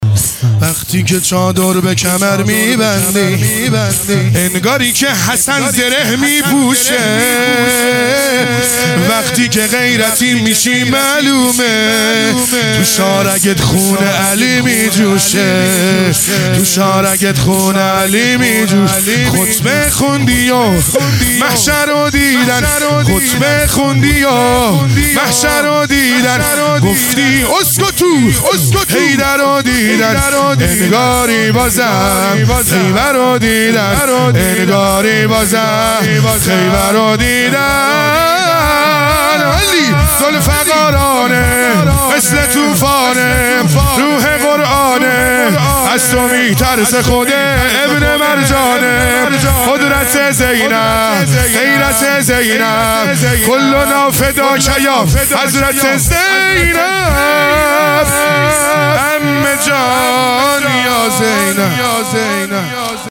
دهه اول فاطمیه | شب سوم | شور | وقتی که چادر به کمر میبندی